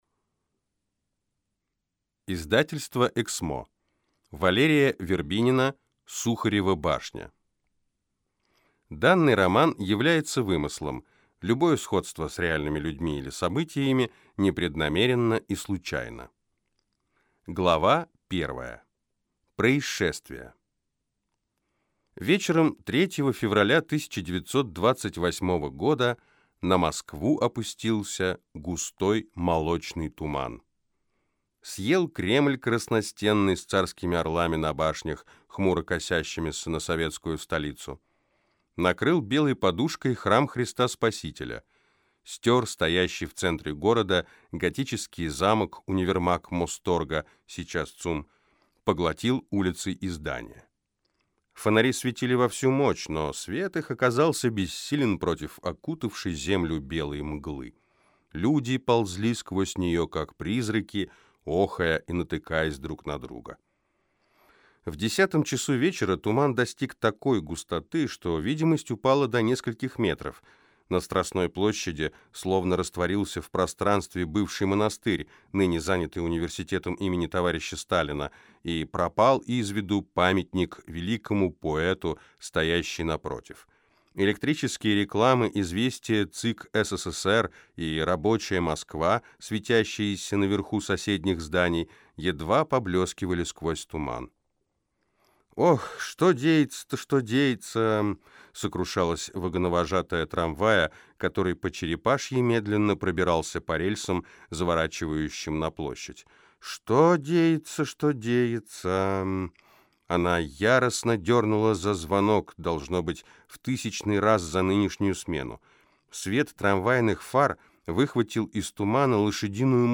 Аудиокнига Сухарева башня | Библиотека аудиокниг